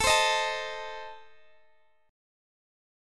Listen to Adim strummed